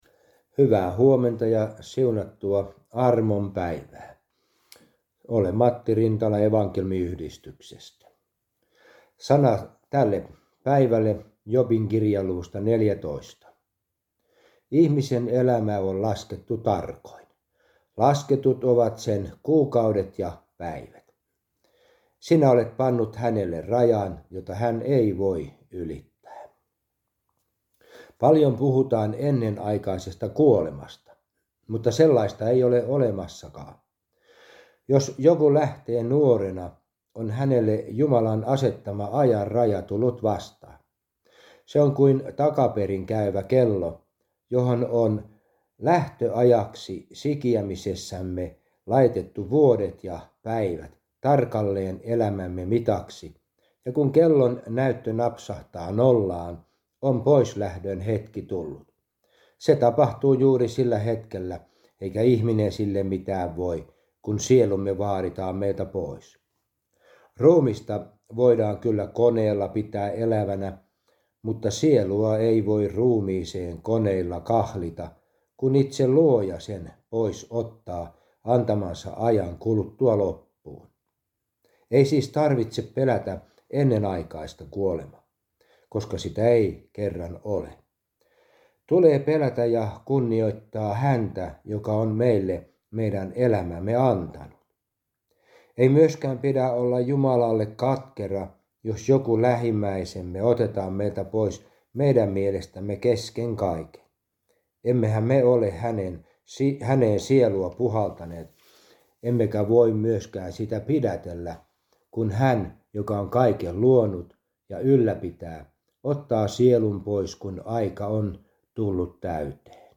Aamuhartaus Järviradioon 25.9.2023
Evankelisen Ylioppilasliiton kuoro; Joht.